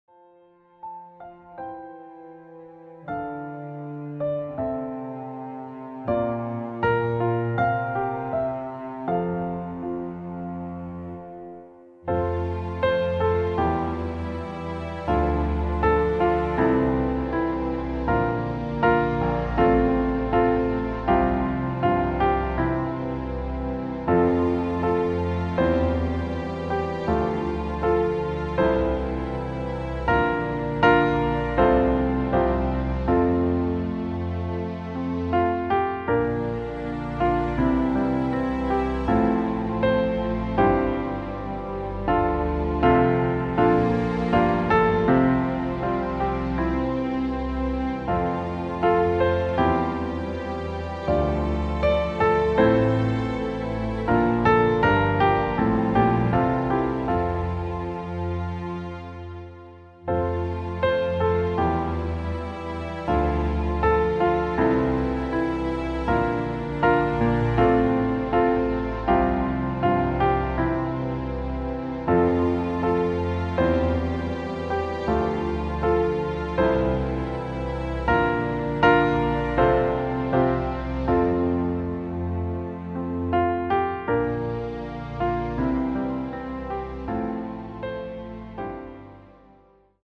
den wir als Klavierversion in unserem Shop anbieten.
Klavier / Streicher